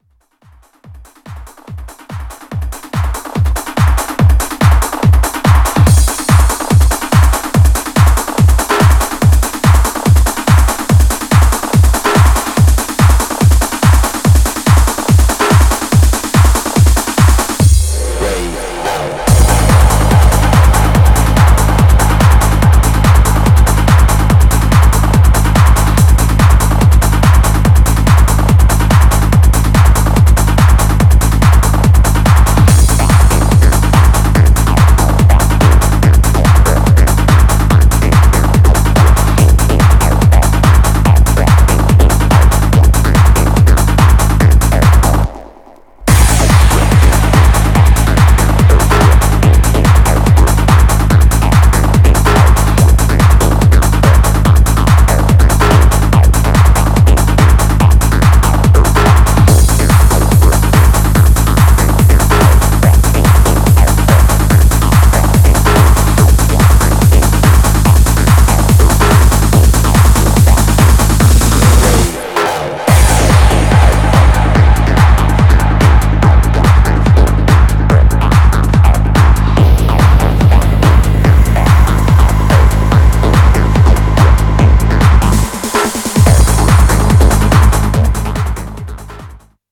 Styl: Techno, Trance